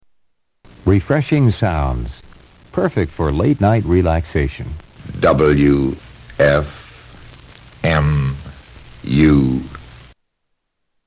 Station ID for WFMU
Category: Radio   Right: Personal